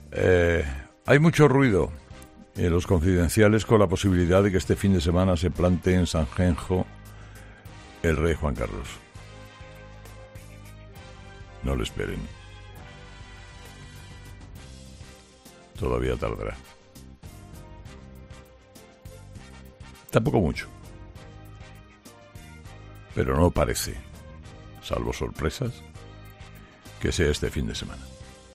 El presentador de 'Herrera en COPE' ha contestado a los rumores sobre la presencia del Rey emérito en nuestro país